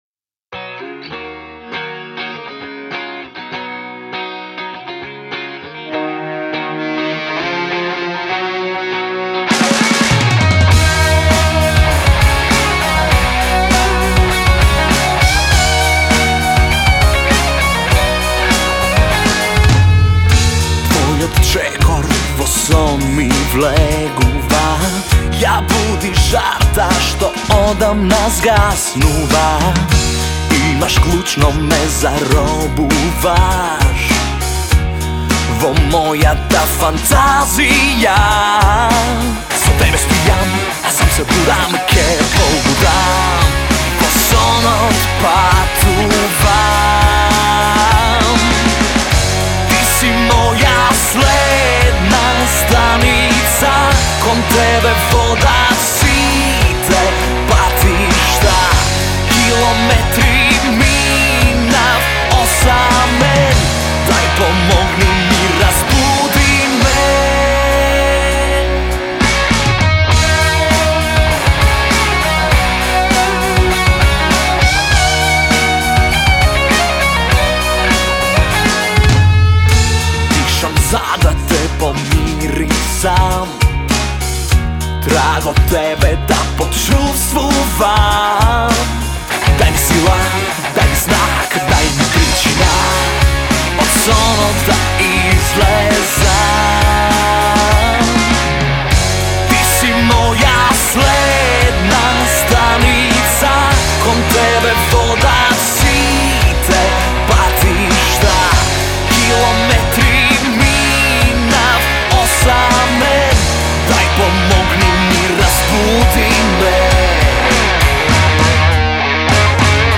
четиричлениот рок состав
оригинален рок звук